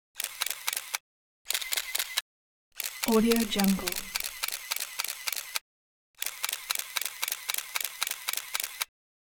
دانلود افکت صوتی شاتر دوربین 35 میلیمتری
Camera Shutter Motorized 35mm Shutters 2 royalty free audio track is a great option for any project that requires miscellaneous sounds and other aspects such as a camera shutter, Motorized 35mm Shutters and multiple clicks.
Sample rate 16-Bit Stereo, 44.1 kHz
Looped No